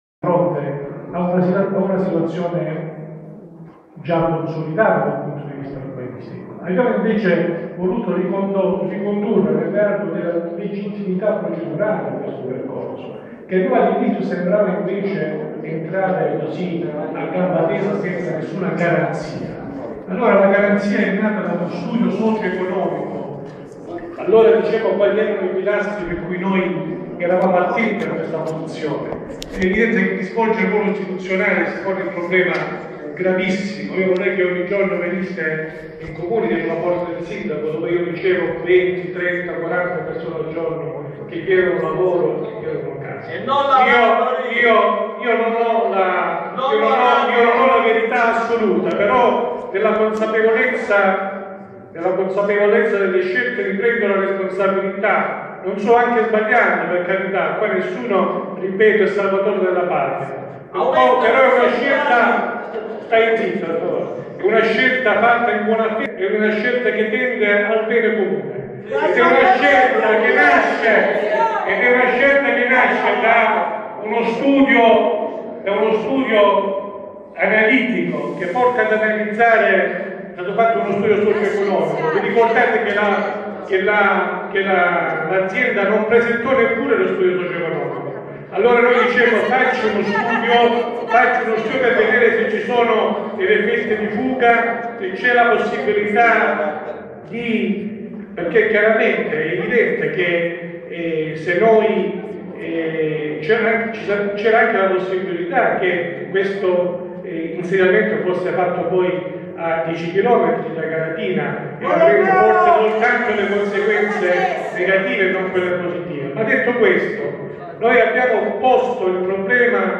Cosimo Montagna alza la voce ma solo per sovrastare le urla di chi dalla tribuna del pubblico contesta, preventivamente e in maniera vivace (in allegato la registrazione), la delibera che il Consiglio comunale sta per approvare per dare il via libera del Comune di Galatina al ‘megaparco’ di Contrada Cascioni.